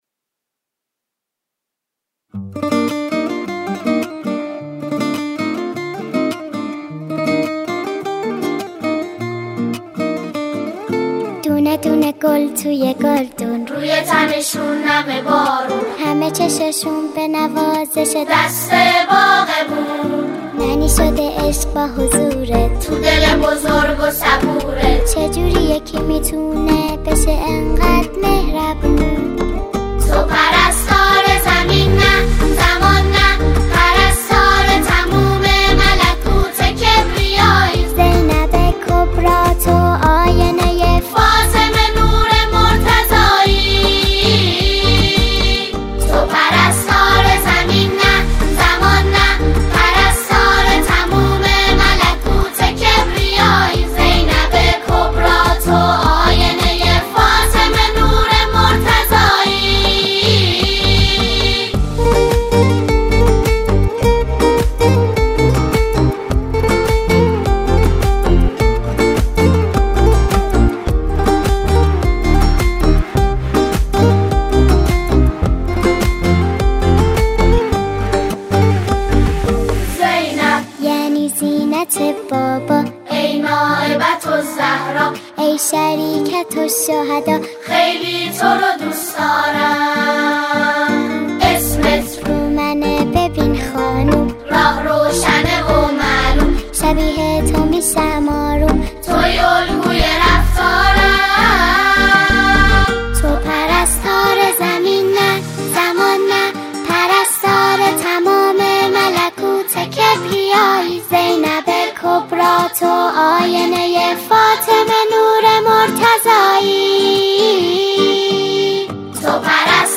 اثری لطیف، کودکانه
ژانر: سرود